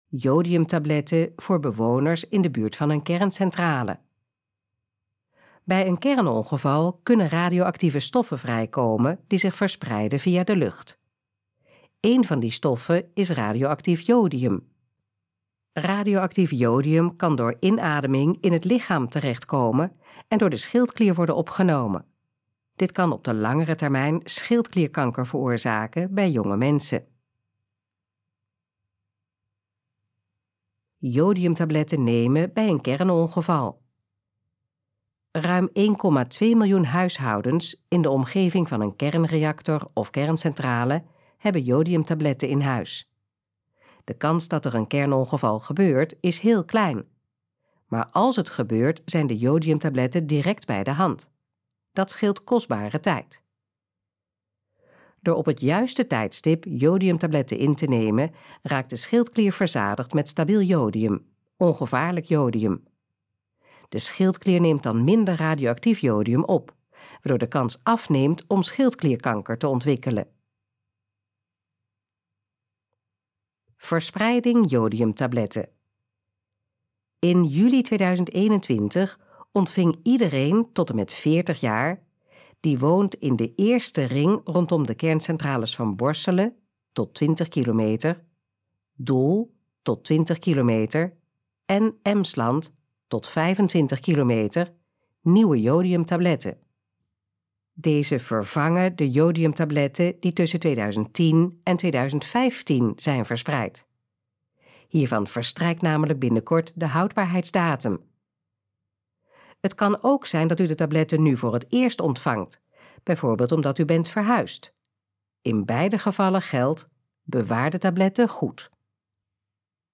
Gesproken versie van: Jodiumtabletten voor bewoners in de buurt van een kerncentrale
Dit geluidsfragment is de gesproken versie van de pagina: Jodiumtabletten voor bewoners in de buurt van een kerncentrale